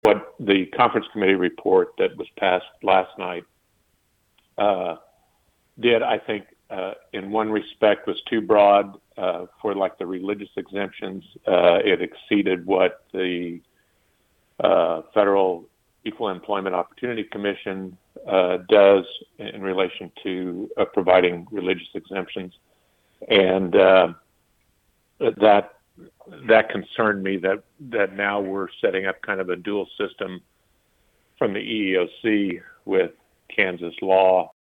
60th District Representative Mark Schreiber of Emporia also voted against the bill. Besides concerns about the financial impact to businesses, he mentioned issues with the religious exemption aspect.